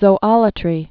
(zō-ŏlə-trē)